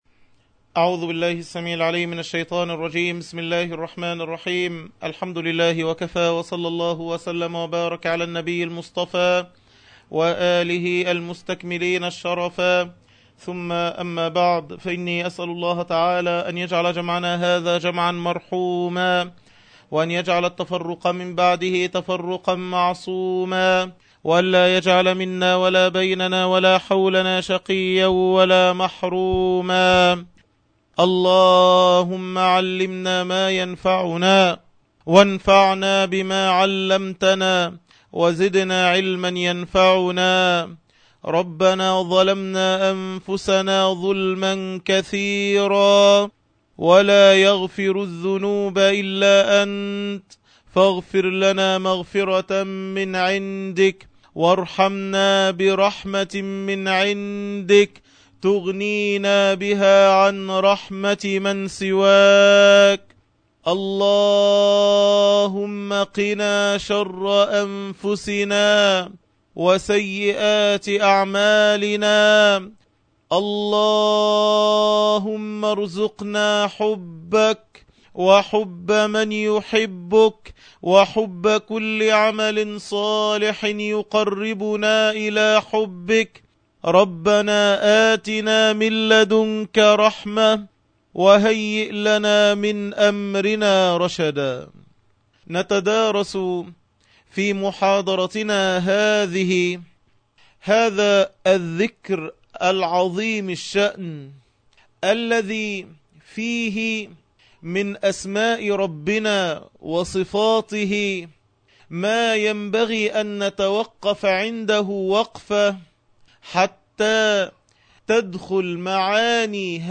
عنوان المادة الدرس الحادي عشر(شرح الاذكار) تاريخ التحميل السبت 30 يونيو 2012 مـ حجم المادة 16.98 ميجا بايت عدد الزيارات 1,280 زيارة عدد مرات الحفظ 588 مرة إستماع المادة حفظ المادة اضف تعليقك أرسل لصديق